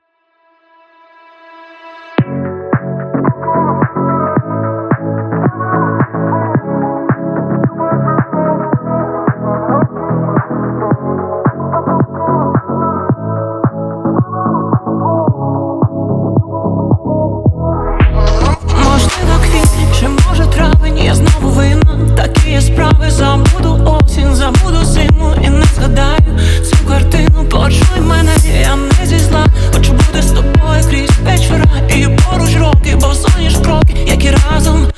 Жанр: Танцевальные / Русские